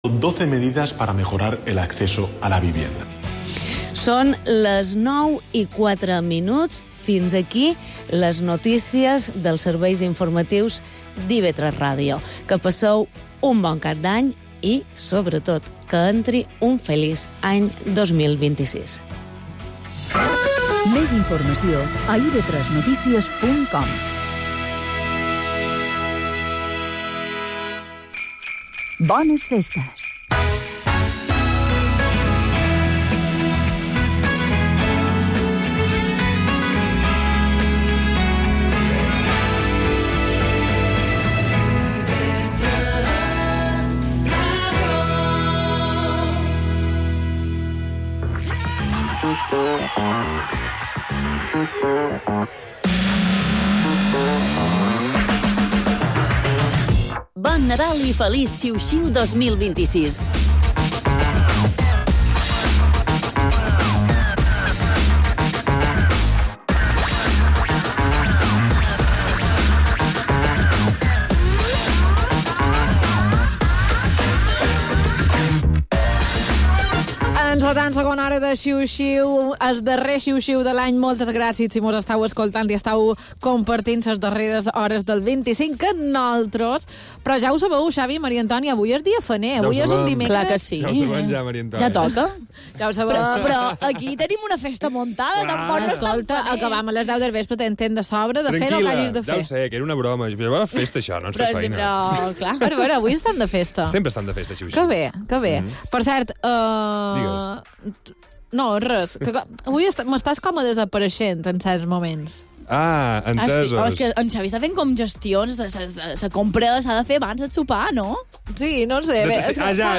Xiu-Xiu és el programa que s’emet de dilluns a divendres als vespres d’IB3 Ràdio per acabar de la millor manera el dia, amb un to d'humor i entreteniment.